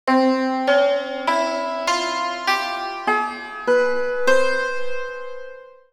عنوان فارسی شرحی یک‌خطی از محتوای این فایل اضافه کنید هلندی Perzische Chahargah muziek-mode
اجرای دستگاه چهارگاه
Chahargah_Mode.wav